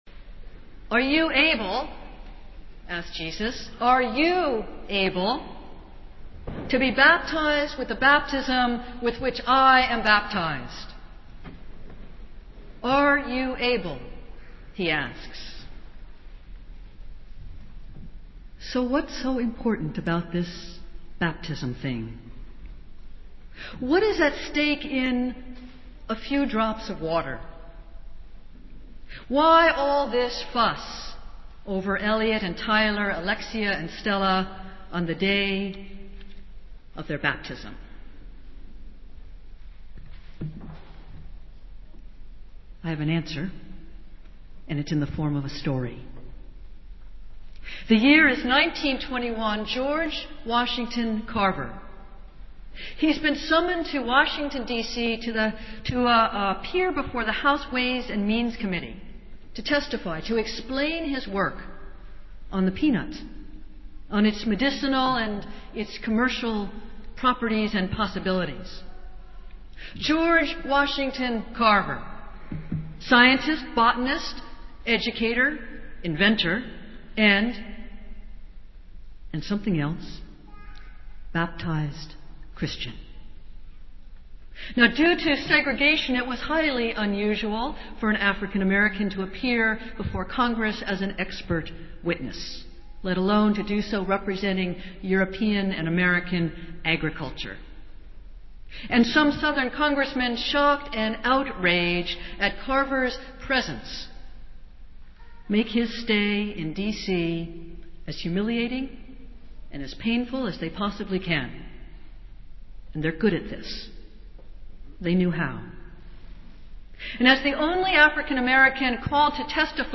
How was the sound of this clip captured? Festival Worship - Second Sunday in Lent